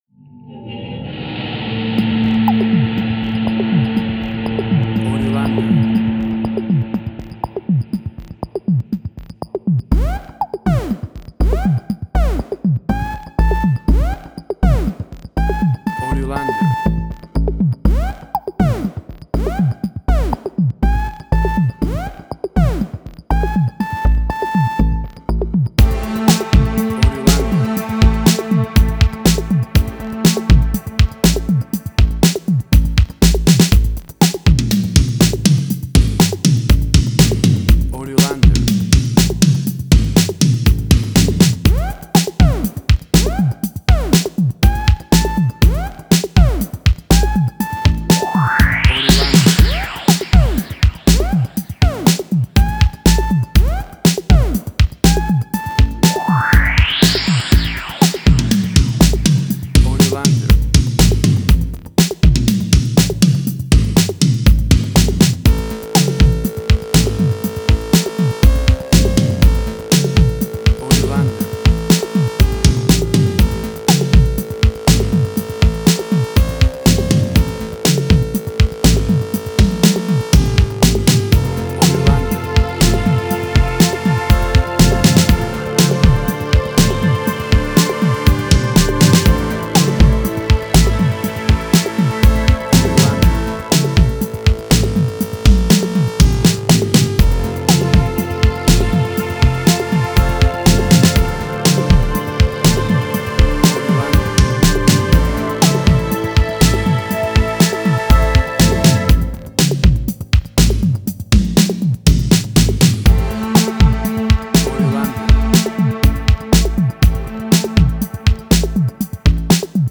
Future Retro Wave Similar Stranger Things New Wave.
Tempo (BPM): 121